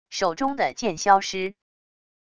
手中的剑消失wav音频